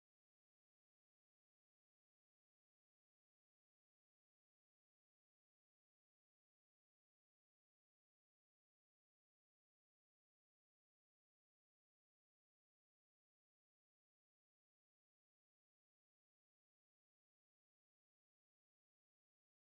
05. Konták 4. hang Szent Lukács apostol.wma